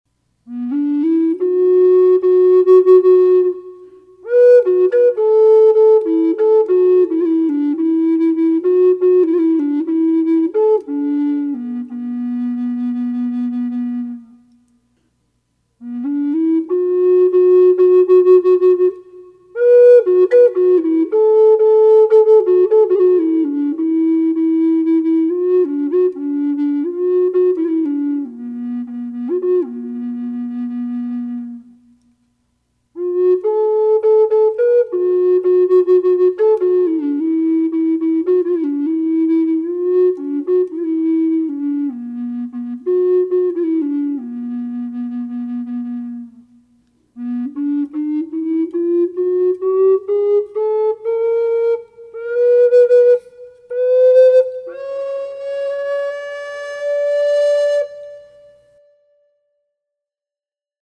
Sound sample of  this Flute short melody + 16 notes scale  with a light reverb